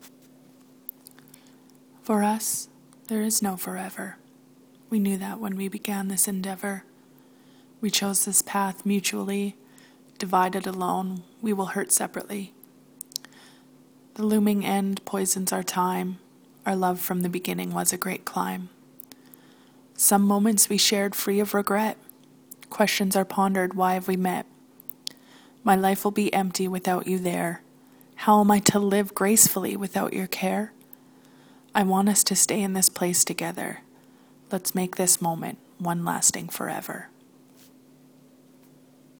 Your personal voice in the audio portion leaves it up to the listener to decide how you actually feel about the situation, but the sense of it, for me at least, is that it’s not particularly troublesome to you, even though there clearly is some disappointment about the outcome.
There is much to admire about this work and your rendering of the work with your voice is perfectly ambiguous.